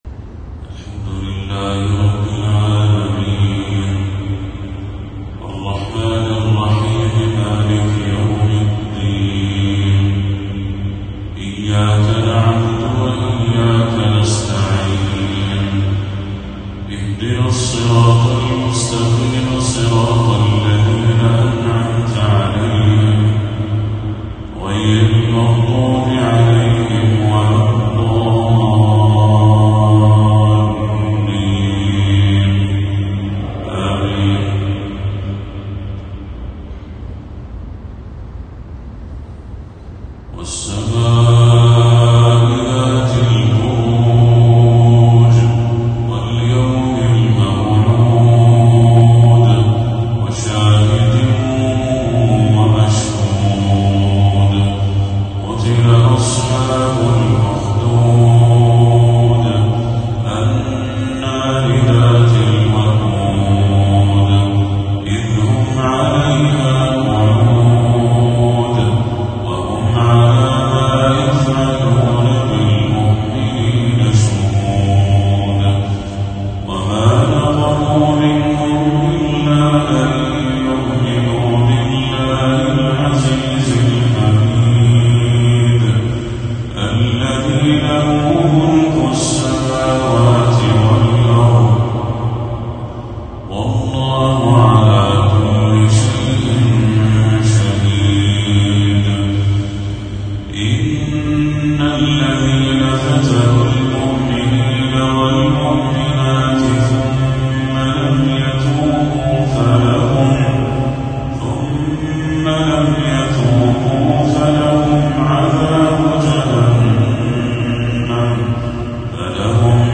تلاوة جميلة لسورة البروج